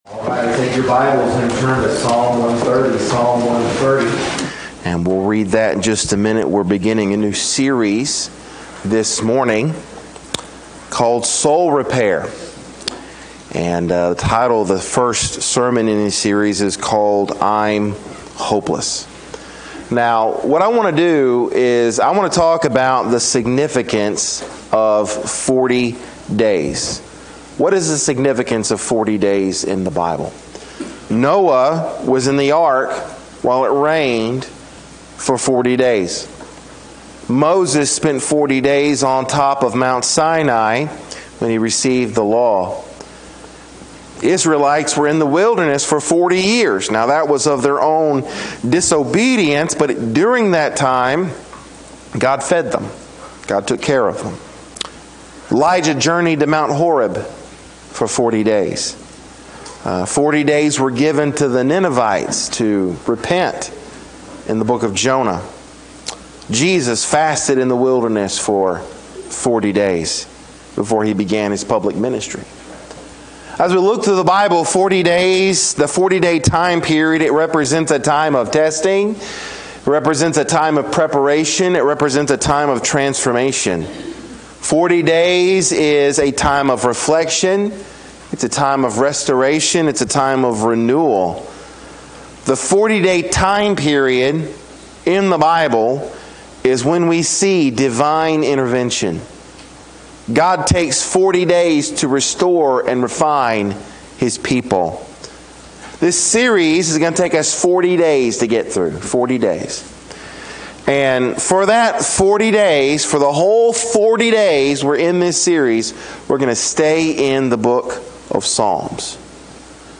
Sermons | Rocky Point Baptist Church